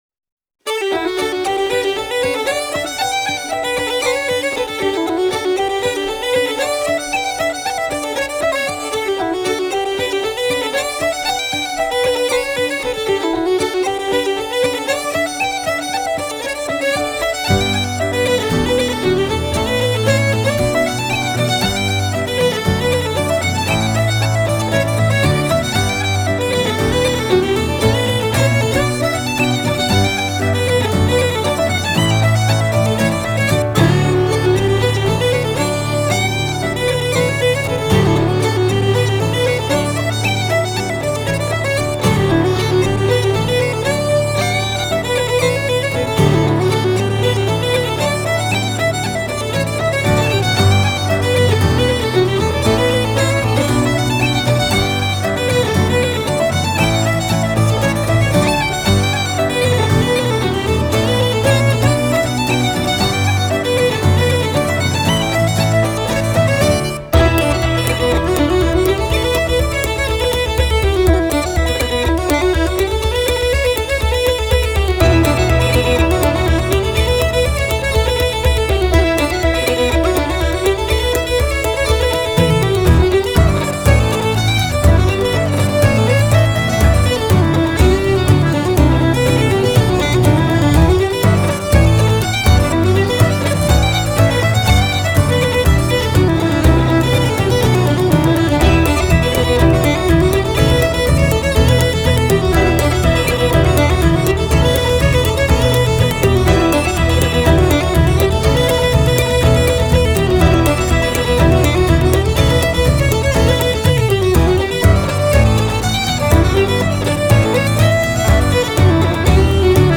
Genre: World, Folk, Celtic, Contemporary Celtic